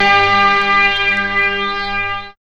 0507R FL.PAD.wav